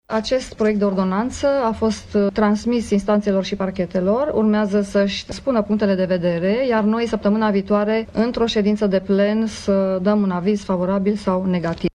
Proiectul ordonanţei de urgenţă prin care va fi modificată ordonanţa 7 referitoare la legile justiţiei a ajuns la Consiliul Superior al Magistraturii, care urmează să dea un aviz asupra documentului, a anunţat în această seară preşedintele CSM, judecătoarea Lia Savonea: